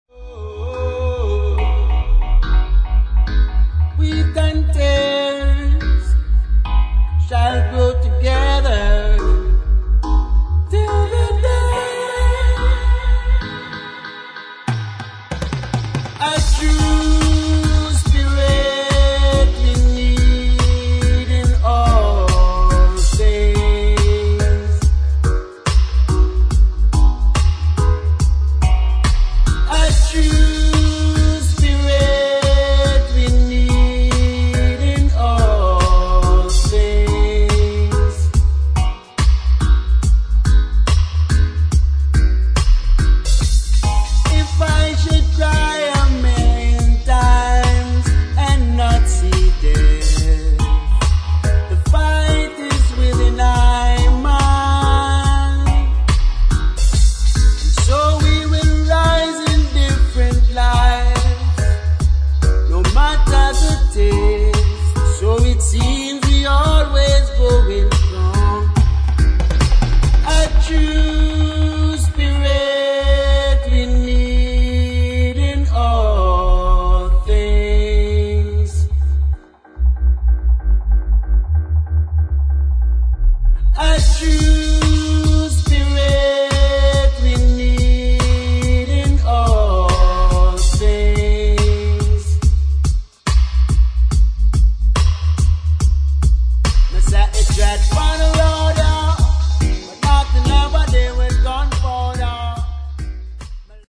[ REGGAE | DUB ]